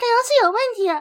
Techmino/media/effect/chiptune/error.ogg at 98050f99beec9cbdd35163c7cabc511de3d7090c
error.ogg